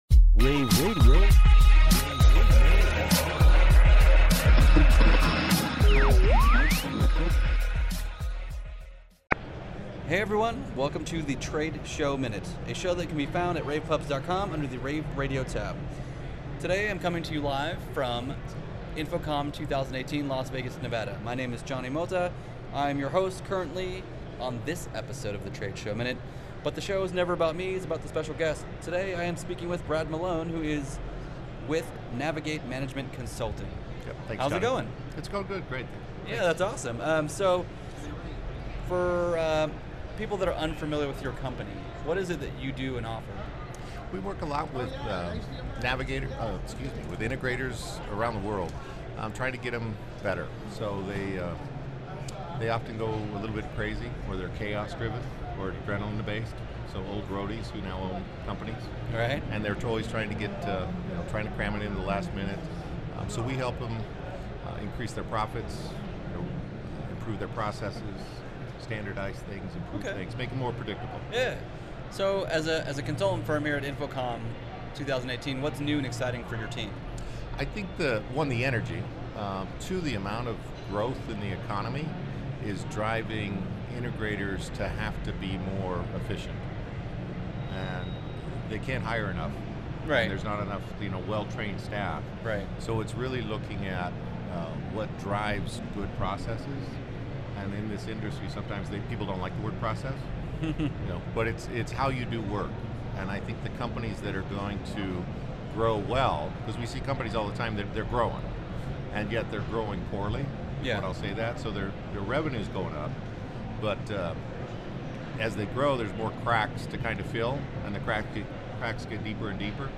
InfoComm Day2_showmin-242.mp3